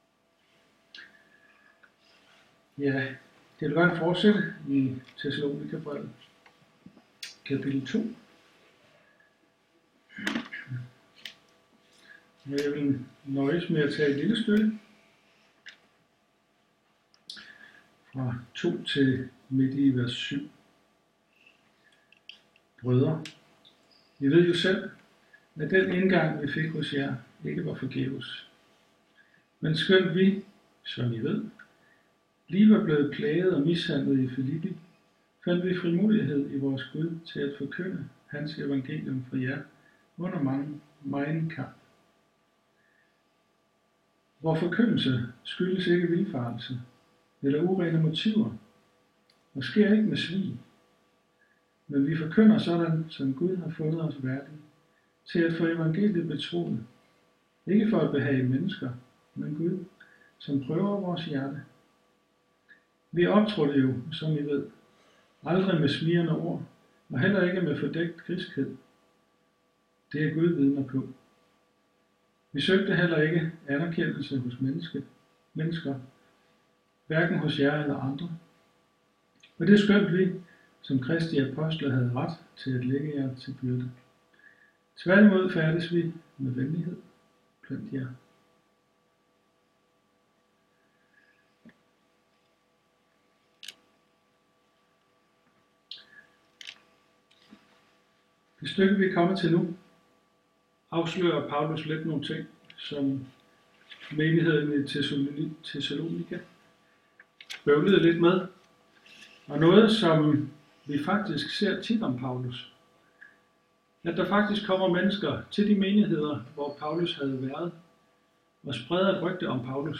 Tale-11.11.m4a